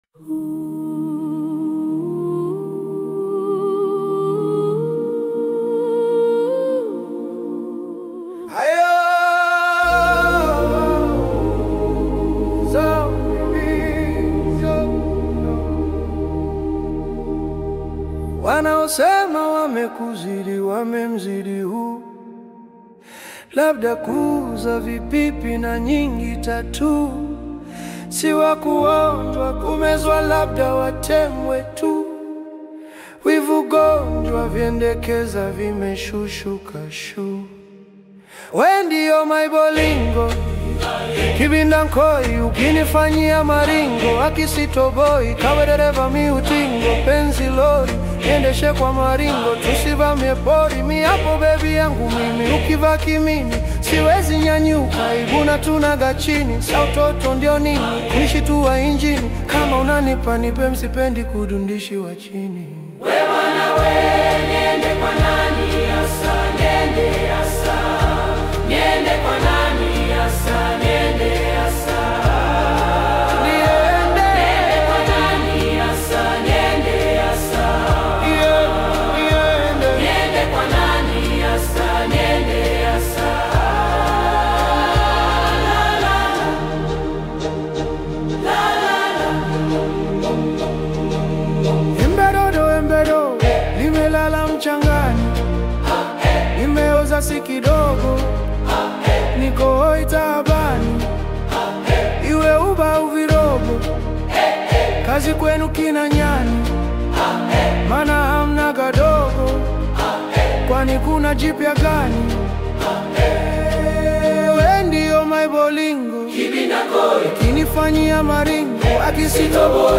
a powerful and emotional reimagination